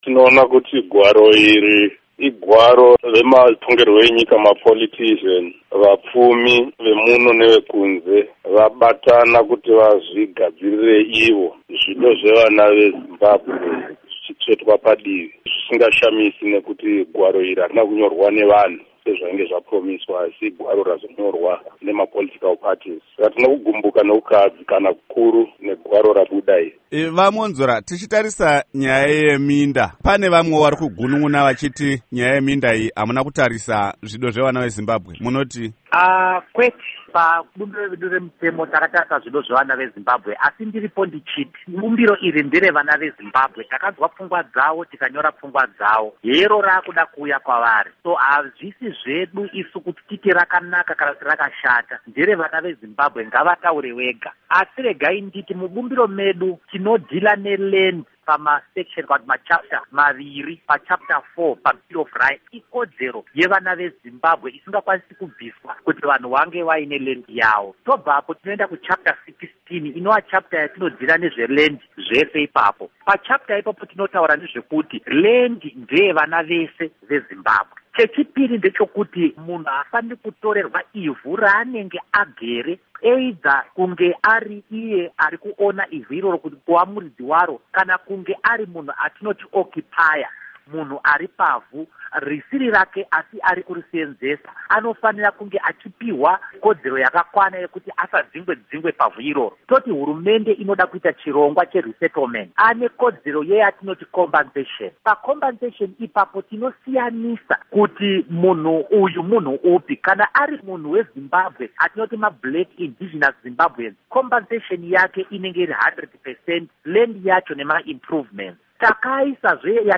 Hurukuro naVaMunyaradzi Gwisai naVaDouglas Mwonzora